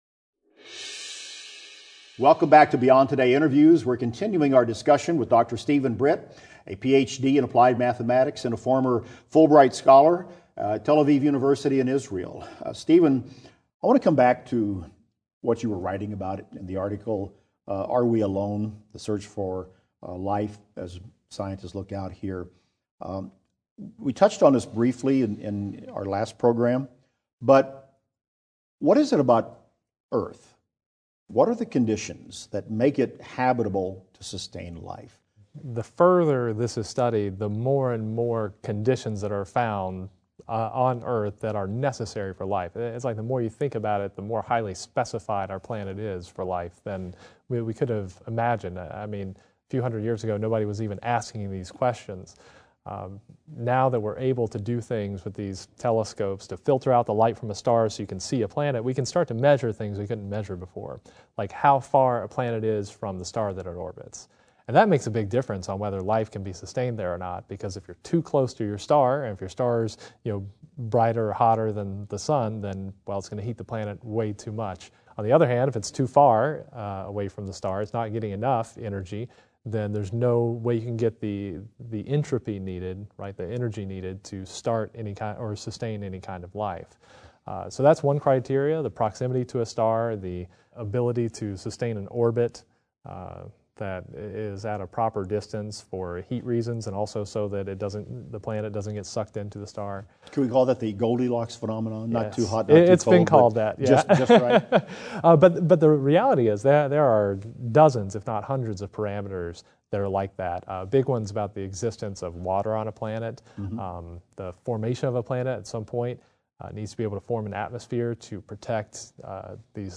Beyond Today Interview: The Search for Extraterrestrial Life - Part 2